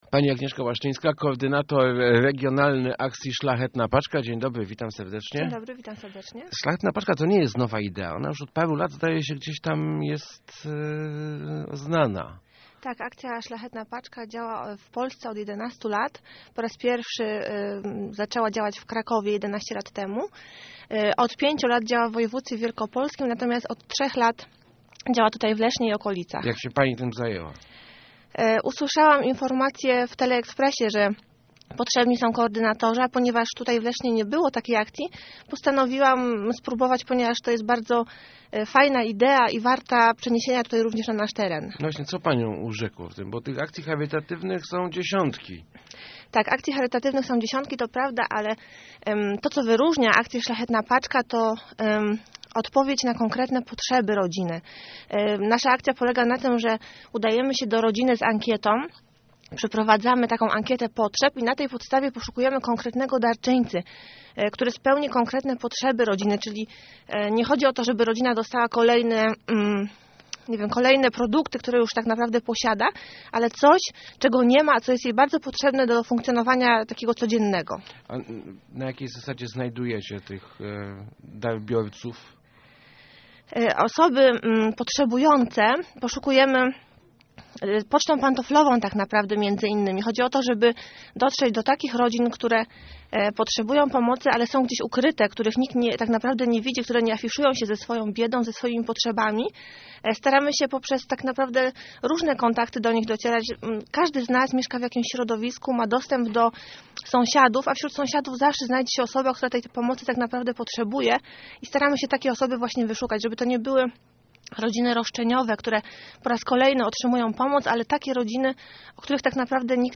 Każdy może zostać Świętym Mikołajem - mówiła w Rozmowach Elki